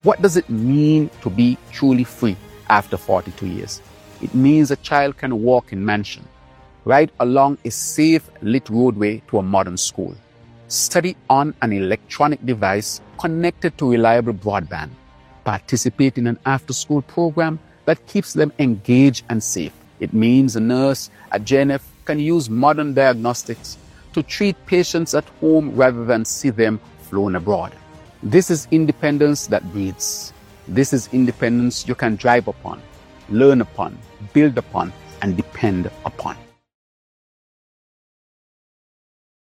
Prime Minister, the Hon. Dr. Terrance Drew, in his independence address to the nation provided these examples.